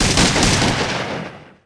Shotgun_BurstShot.ogg